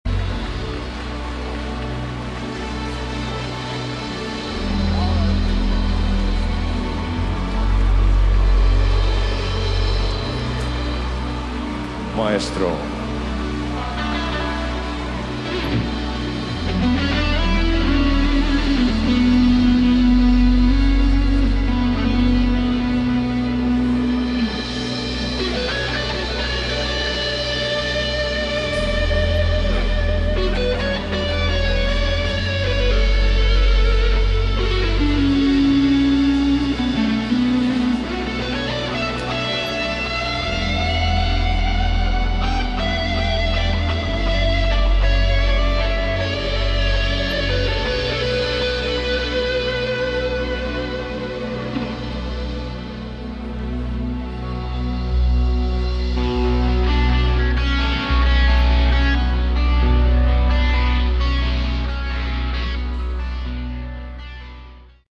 Category: Melodic Metal
vocals
guitar
keyboards
bass
drums